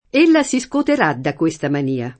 mania [man&a] s. f. — es. con acc. scr.: ella si scoterà da questa manìa [